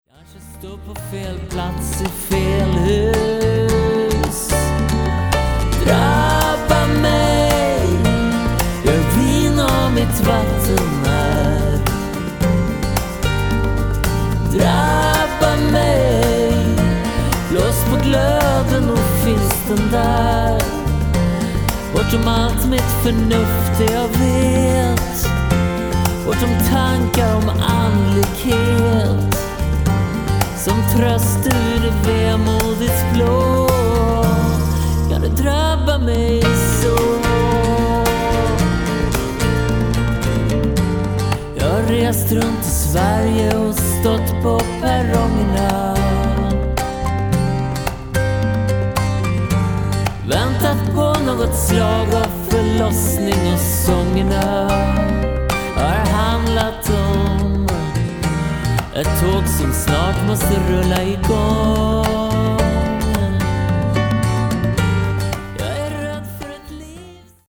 Bäst just nu är en sprudlande låt som heter